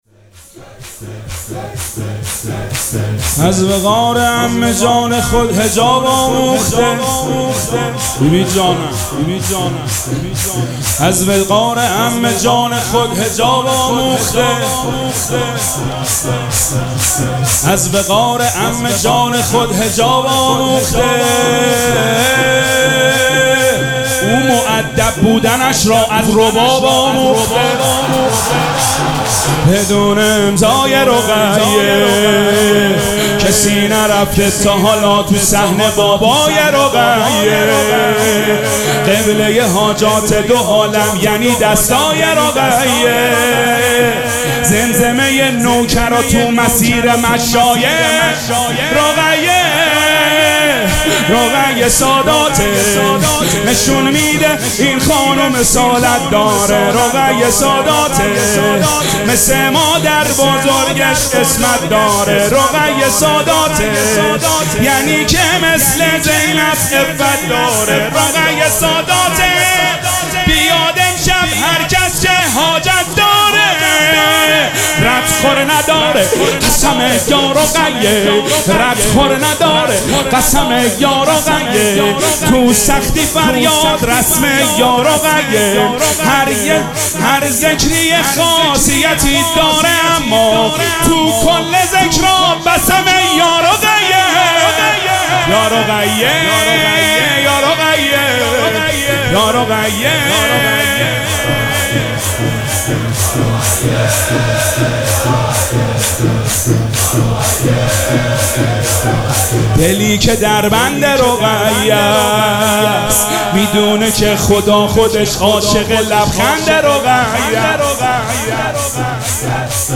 مراسم عزاداری شب سوم محرم الحرام ۱۴۴۷
شور